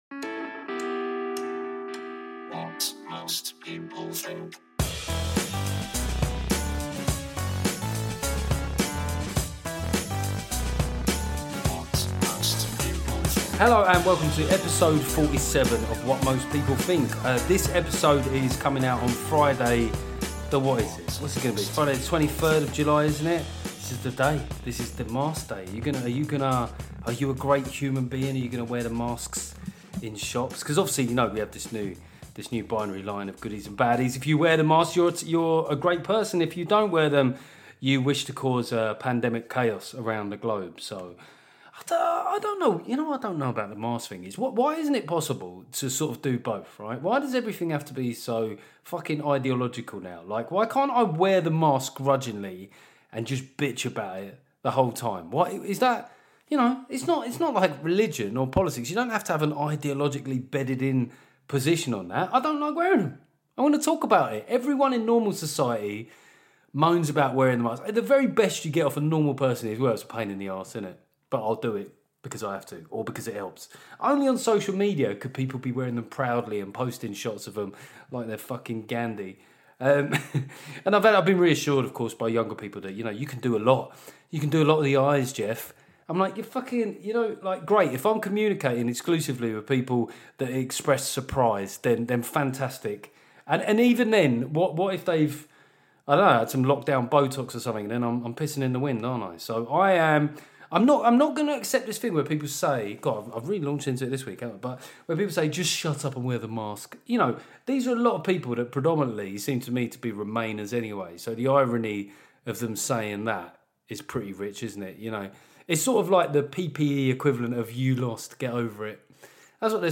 We ask challenging questions. It's political, sometimes intense, sometimes a laugh.